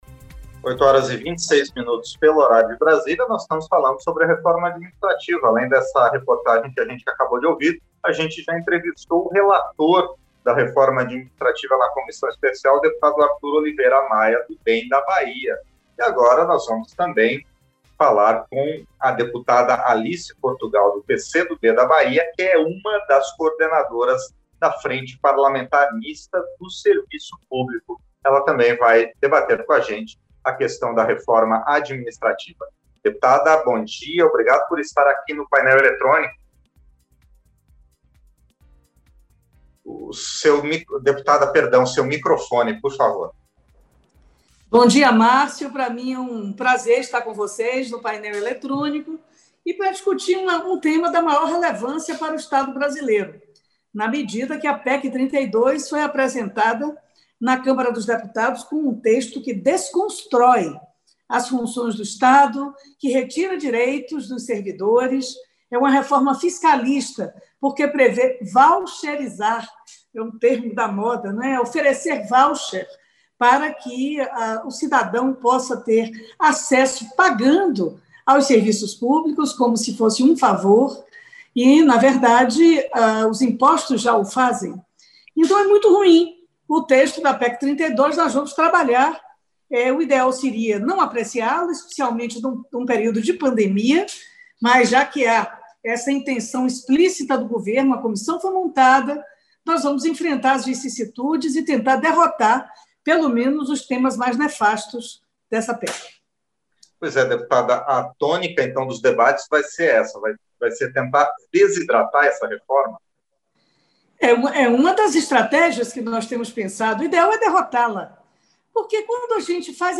Entrevista - Dep. Alice Portgual (PCdoB-BA)